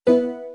U.I. Sound 0.wav